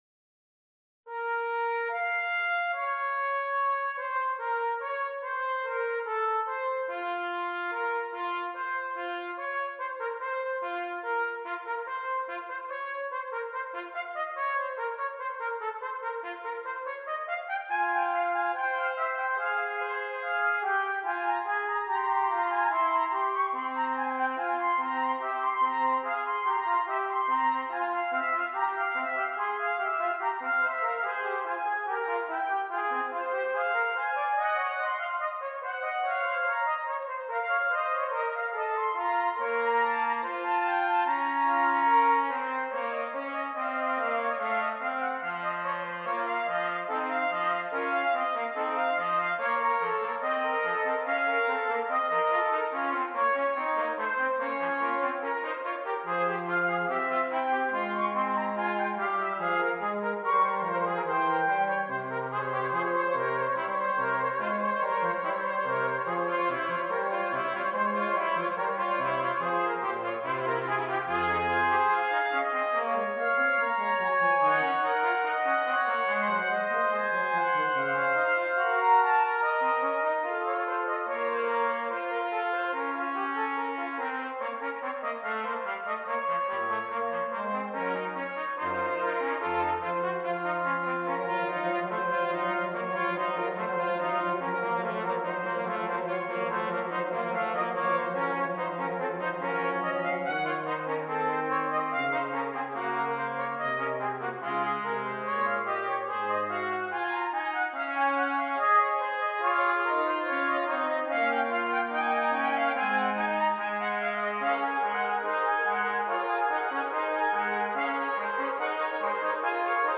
4 Trumpets and Bass Clef Instrument